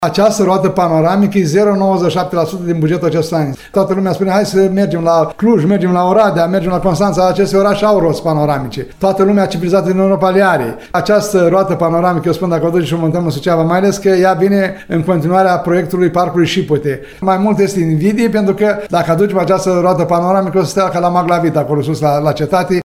El a declarat postului nostru că mulți critici de pe rețelele de socializare “sunt invidioși” pentru realizările administrației locale.